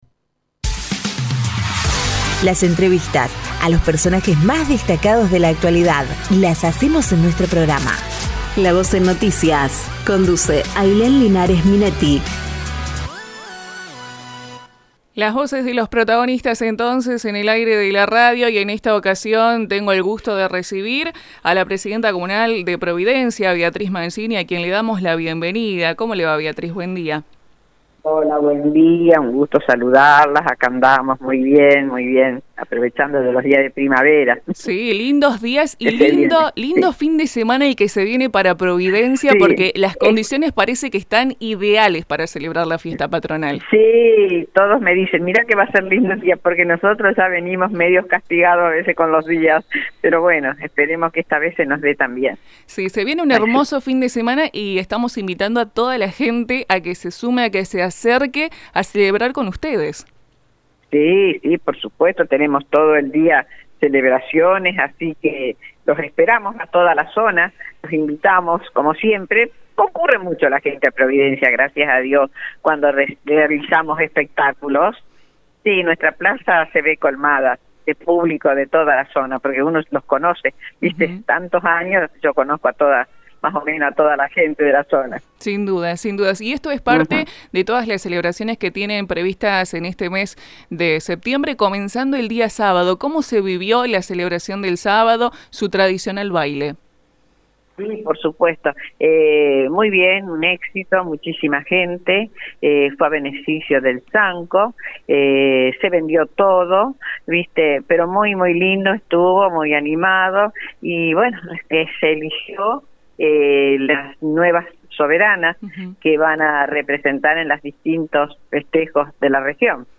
Escucha la entrevista completa con la presidenta comunal de la localidad, Beatriz Mangini.